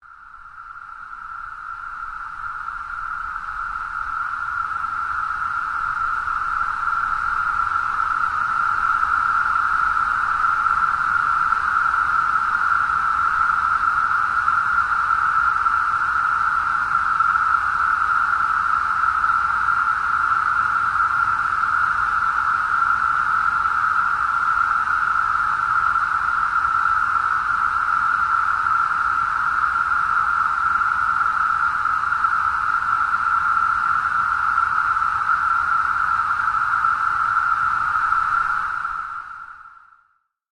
Mega Locust Swarm
Animals
yt_6FEE8jiGlnw_mega_locust_swarm.mp3